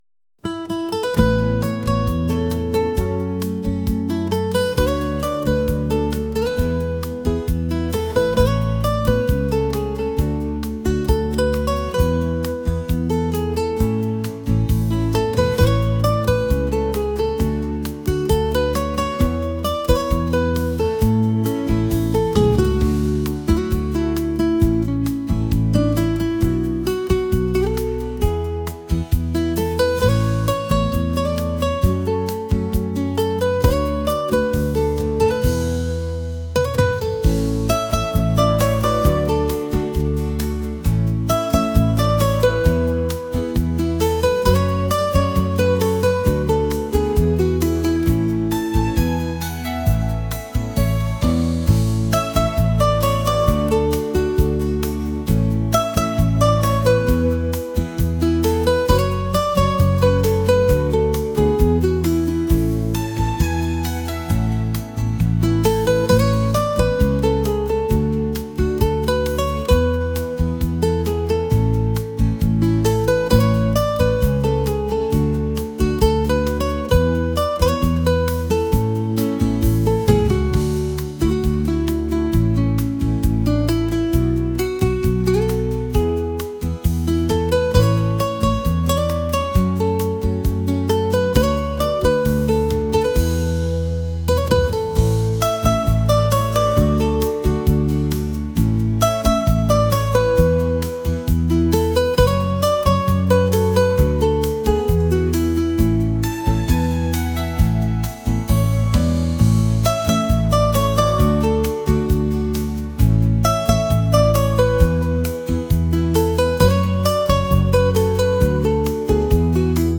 acoustic | pop | ambient